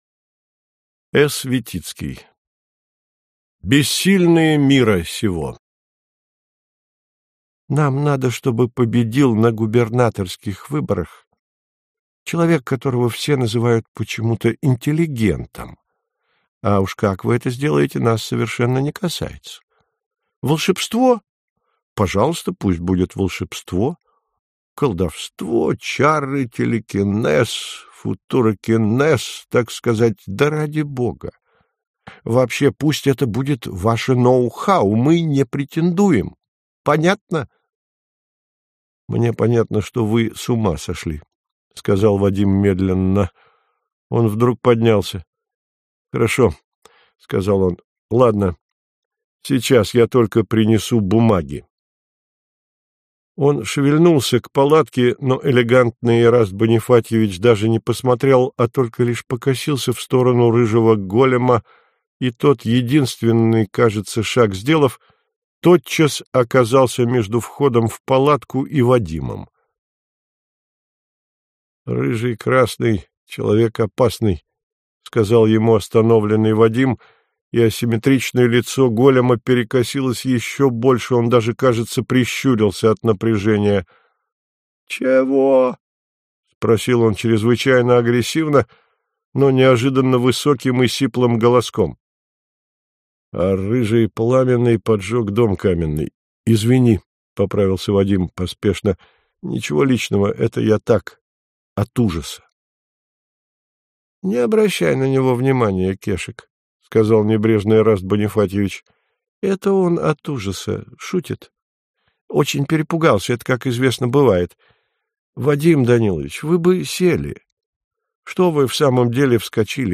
Аудиокнига Бессильные мира сего - купить, скачать и слушать онлайн | КнигоПоиск